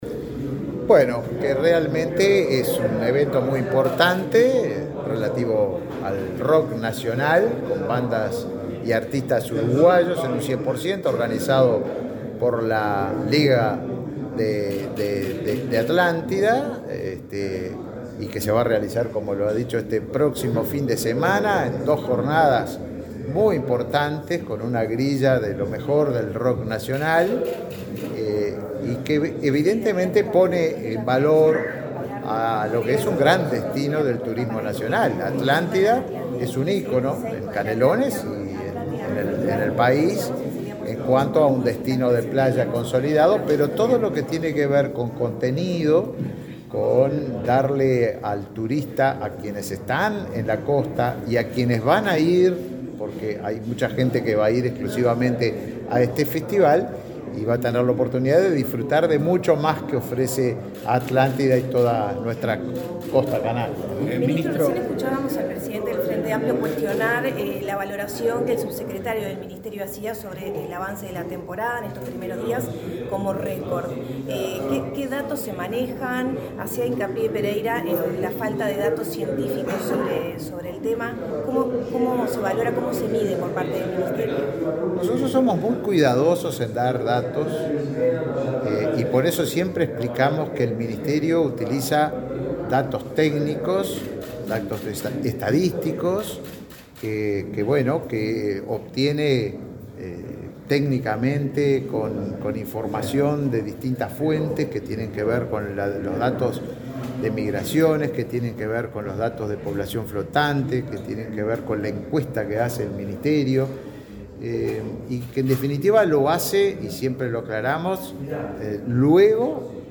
Declaraciones del ministro de Turismo
El ministro de Turismo, Tabaré Viera, participó en Montevideo del lanzamiento del Atlántida Rock Festival. Luego, dialogó con la prensa.